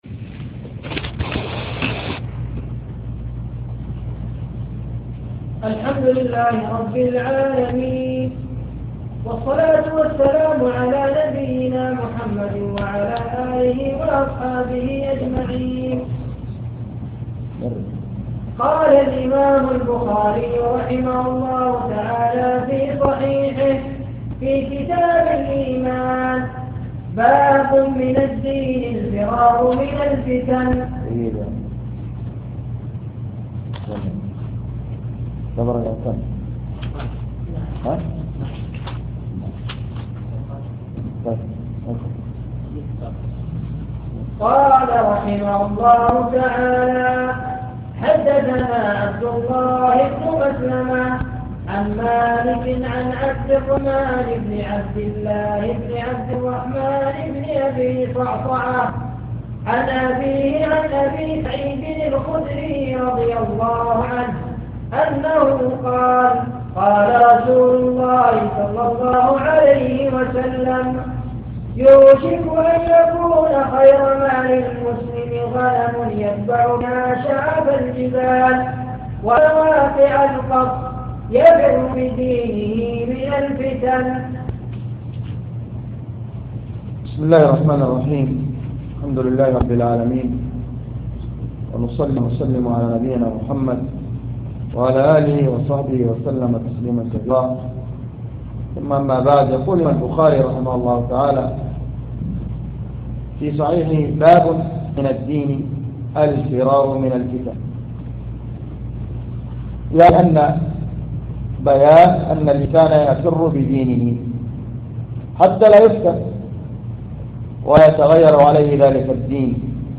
سلسلة دروس شرح صحيح البخاري - شرح مسجد الفتاح بضمد